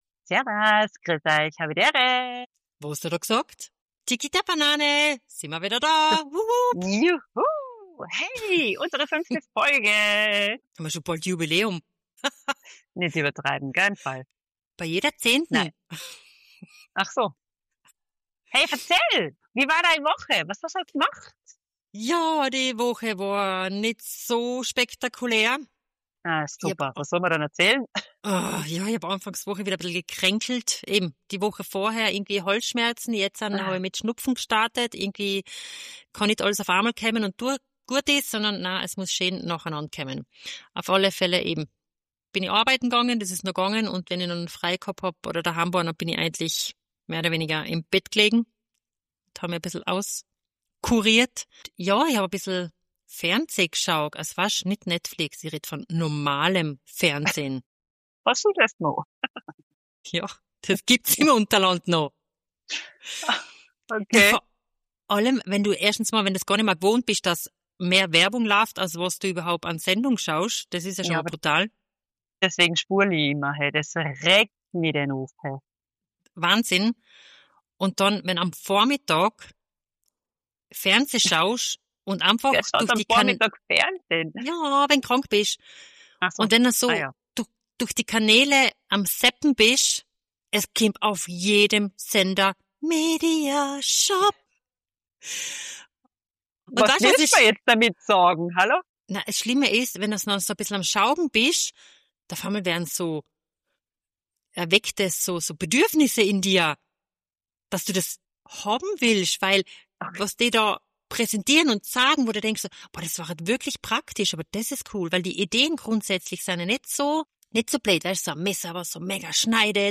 2 Schwestern aus Österreich in der Schweiz